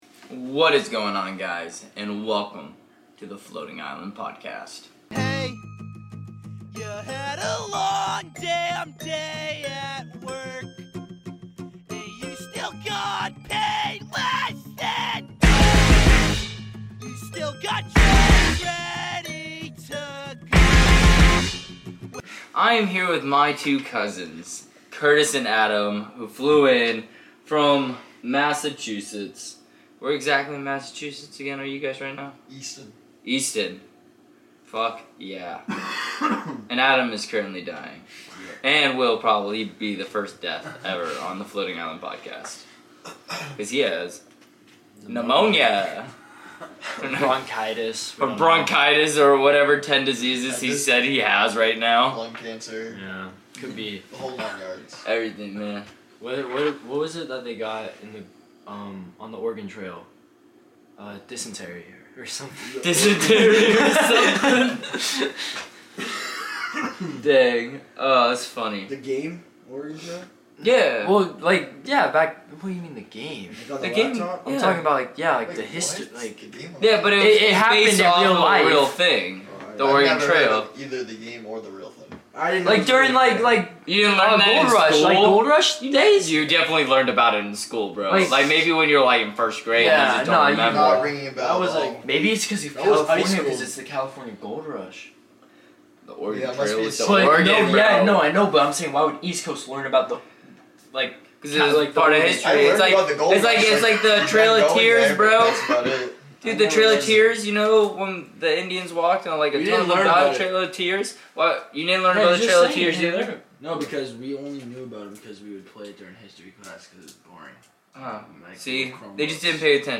A very laid back episode and fun conversations. How do you say pink?